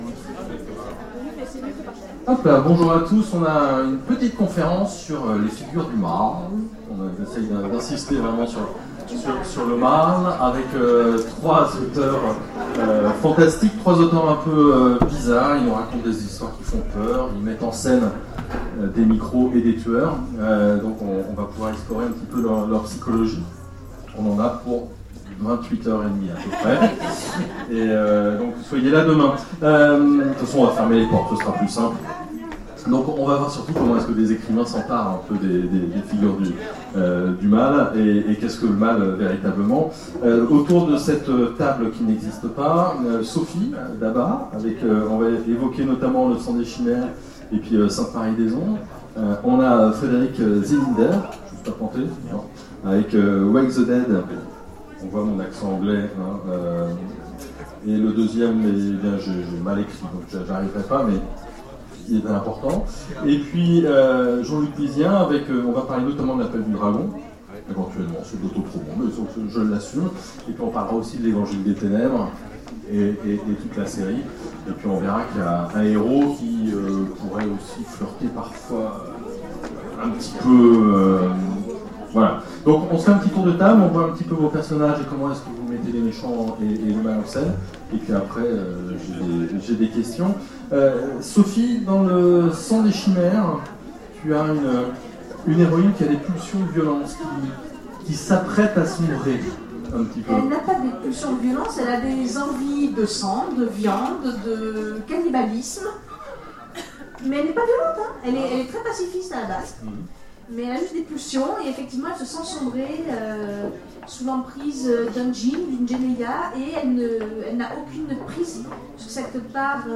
Autres Mondes 2017 : Conférence Écrire des horreur...
actusf_autres_mondes_2017_conference_maitre_du_mal.mp3